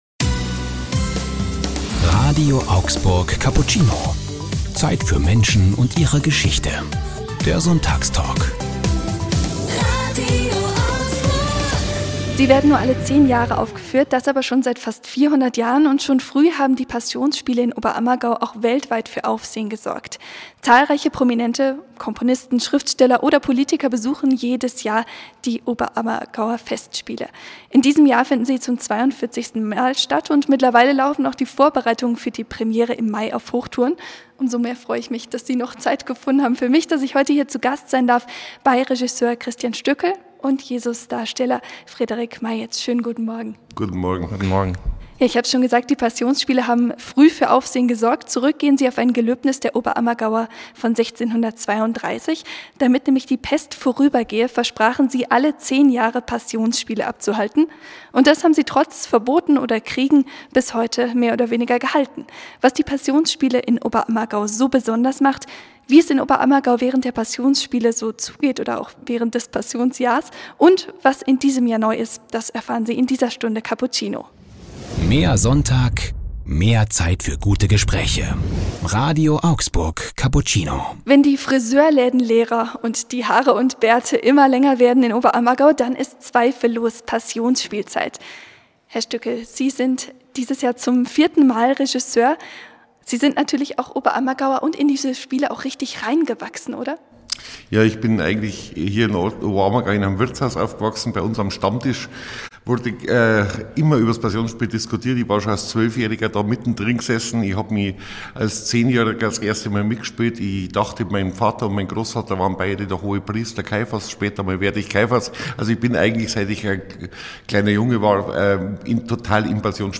Cappuccino on Tour: zu Gast bei den Passionsspielen Oberammergau ~ RADIO AUGSBURG Cappuccino Podcast